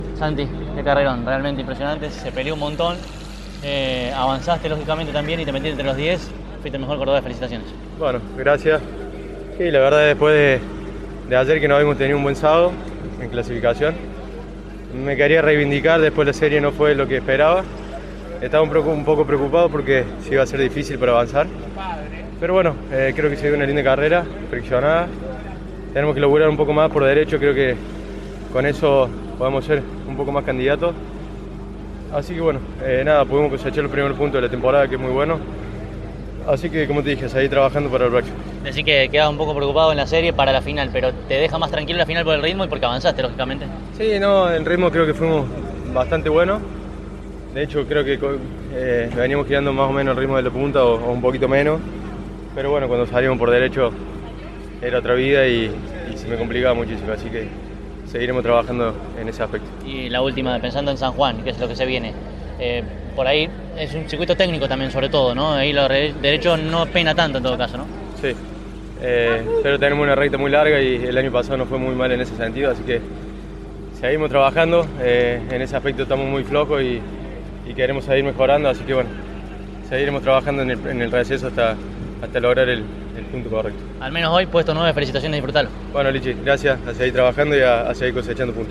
En una nueva cobertura de una competencia del TN de este medio, tanto los tres mejores de la final de la divisional mayor, como así también el mejor cordobés posicionado, fueron aquí entrevistados.